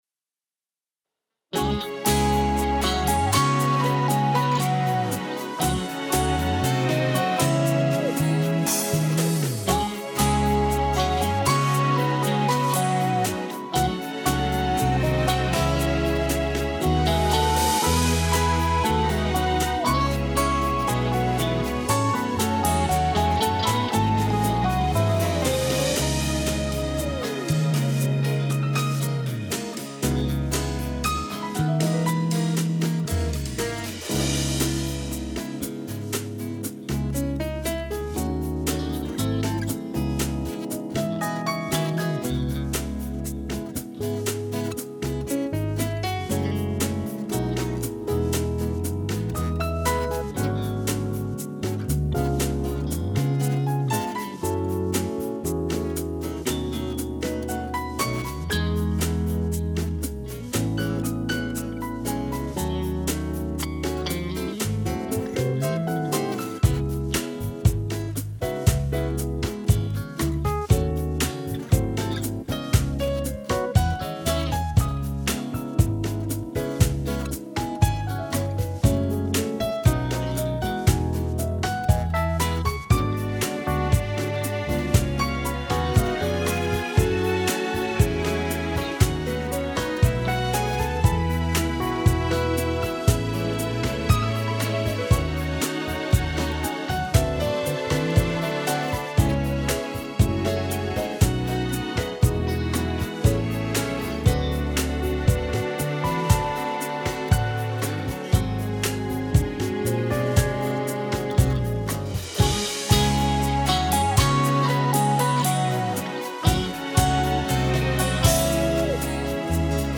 минусовка версия 249287